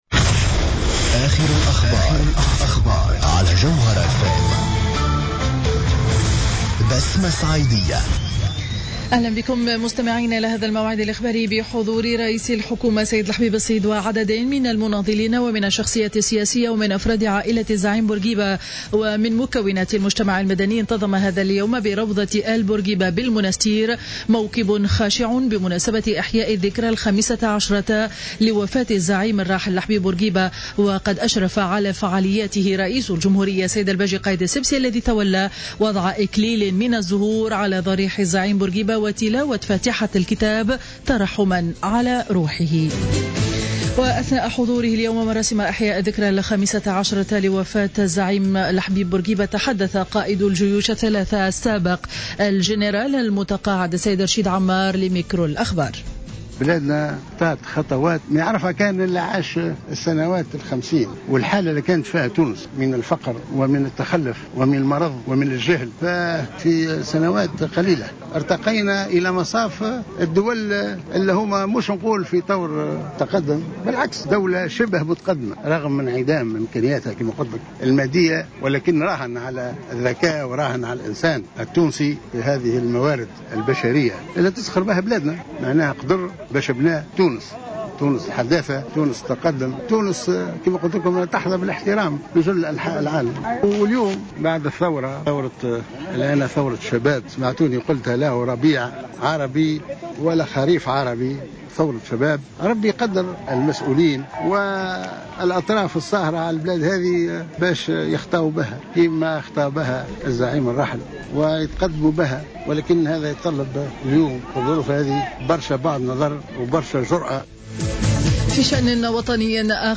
نشرة أخبار منتصف النهار ليوم الاثنين 06 أفريل 2015